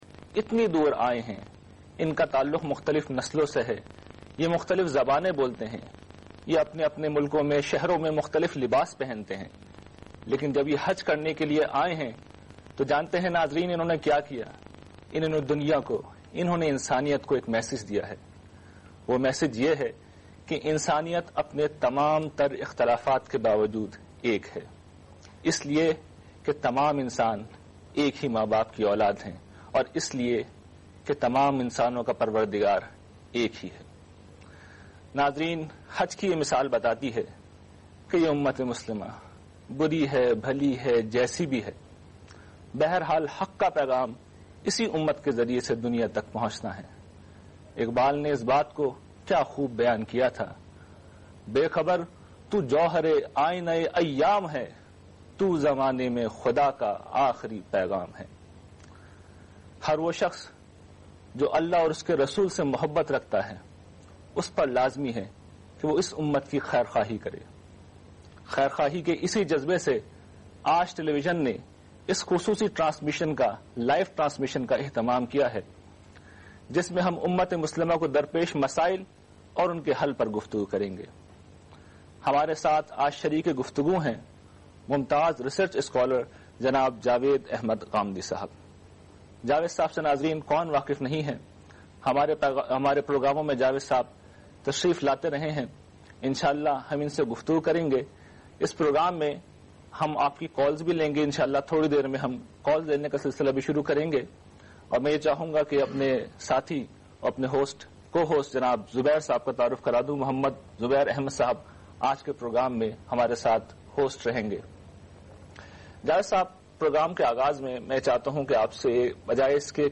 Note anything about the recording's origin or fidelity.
The program was aired on Aaj TV (in Pakistan) in the year 2006.